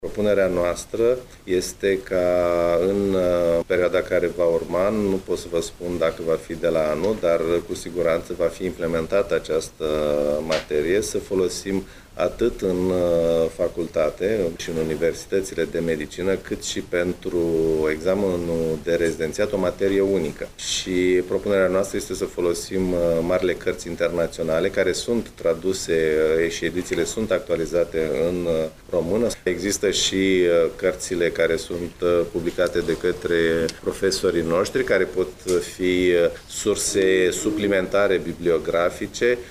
Cel mai probabil, astăzi, vor fi afişate repartiţiile la examenul de rezidenţiat, a spus aseară ministrul sănătăţii, Victor Costache.
Prezent la Iaşi, el a subliniat importanţa unui examen centralizat corect pentru toată lumea unde se învaţă de pe aceaşi materie, pentru a asigura egalitate de şanse pentru toţi absolvenţii.